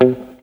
RIFFGTR 09-L.wav